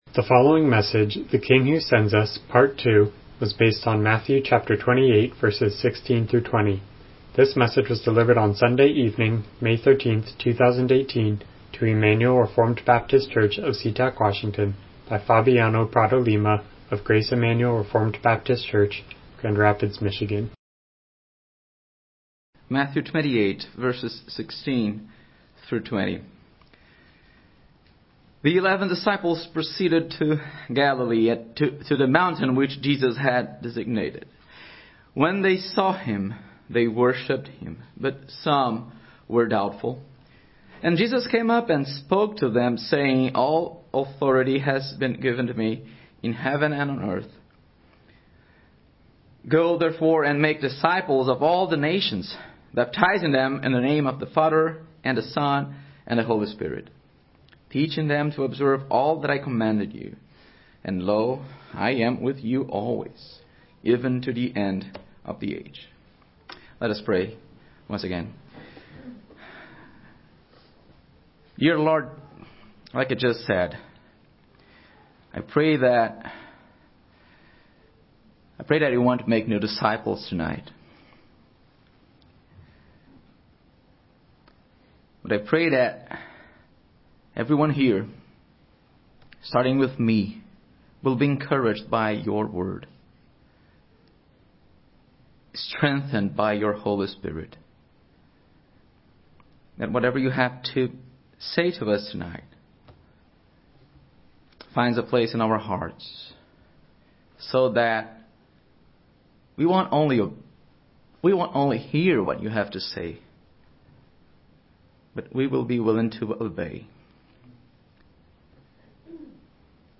Miscellaneous Passage: Matthew 28:16-20 Service Type: Evening Worship « The King Who Sends Us